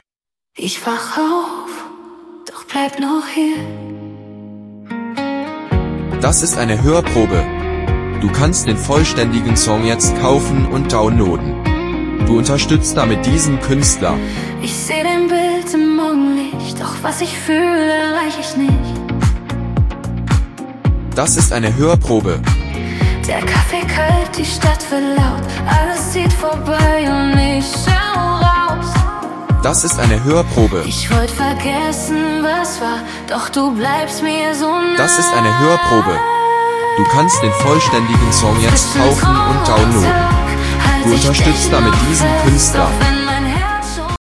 gefühlvollen Deutschpop mit Herz und Tiefe
sanfte Melancholie mit hoffnungsvoller Leichtigkeit
Produziert im Studio
ist kein lauter Song – sondern einer, der bleibt.